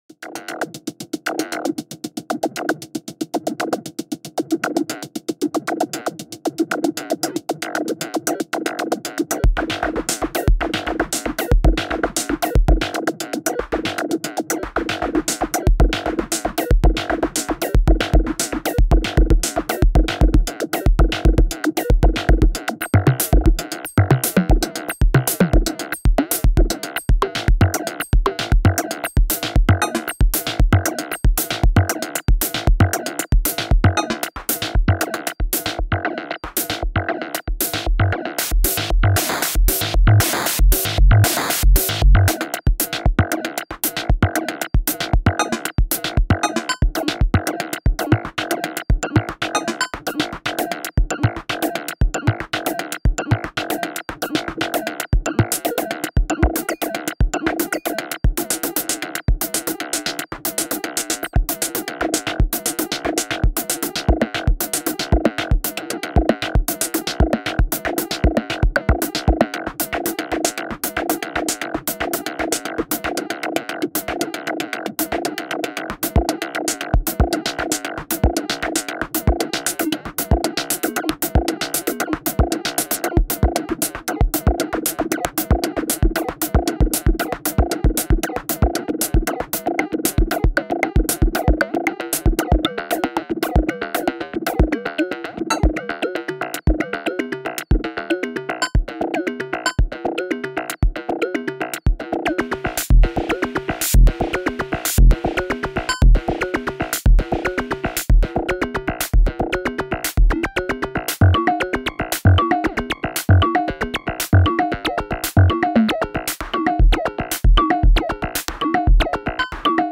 Only 8 steps but I can trigger patch changes and gates with it.
Some of it sounded alright, some not so much!